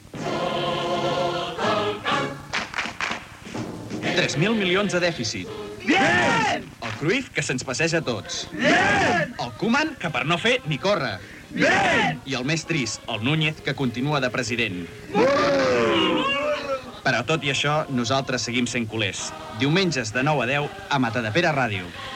Promociò del programa